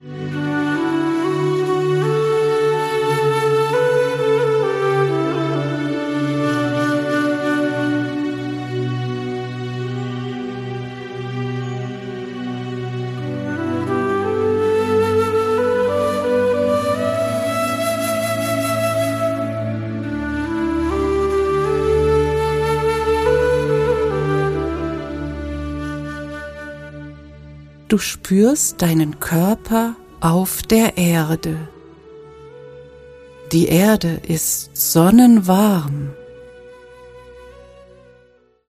Es sind Klänge verschiedener Klangschalen eingefügt.